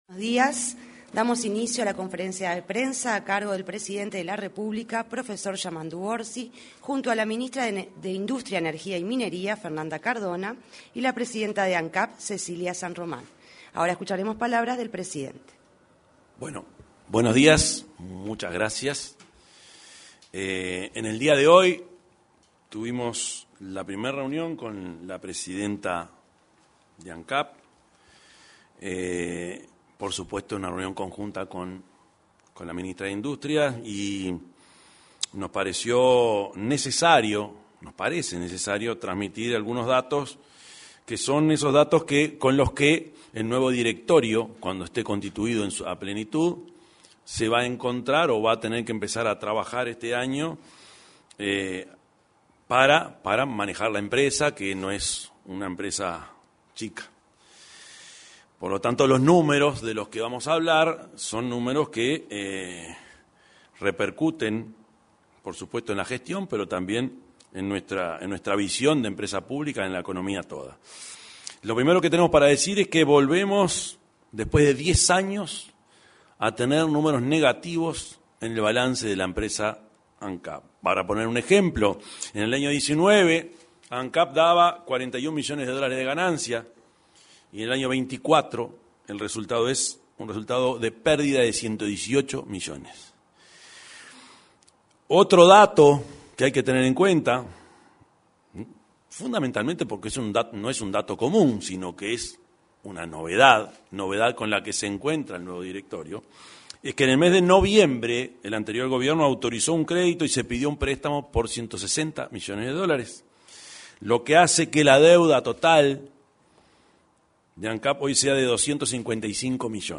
Conferencia de prensa del presidente de la República
Conferencia de prensa del presidente de la República 25/04/2025 Compartir Facebook X Copiar enlace WhatsApp LinkedIn Este viernes 25, en la sala de prensa de la Torre Ejecutiva, se realizó una conferencia de prensa a cargo del presidente de la República, profesor Yamandú Orsi; la ministra de Industria, Energía y Minería, Fernanda Cardona y la presidenta de Ancap, Cecilia San Román.